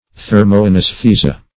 Search Result for " thermoanesthesia" : The Collaborative International Dictionary of English v.0.48: Thermoanaesthesia \Ther`mo*an`aes*the"si*a\ or Thermoanesthesia \Ther`mo*an`es*the"si*a\, n. [NL.]